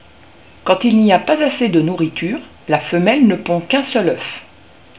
Le cri du hibou